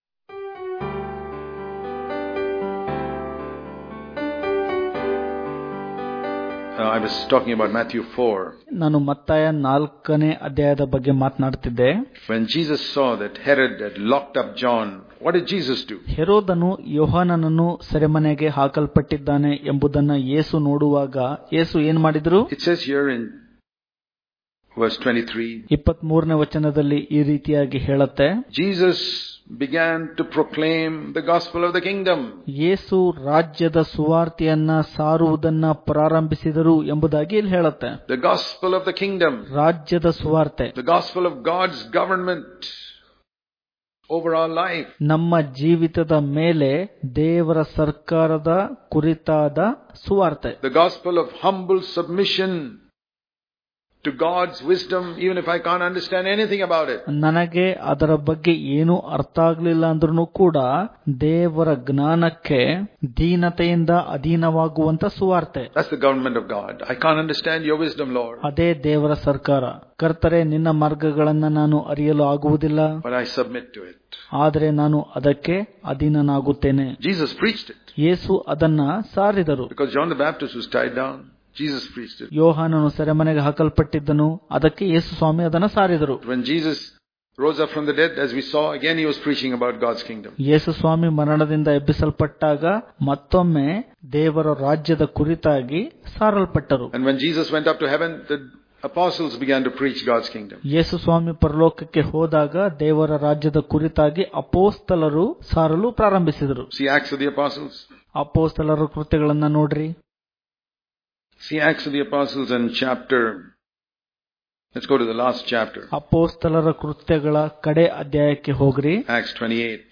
Daily Devotions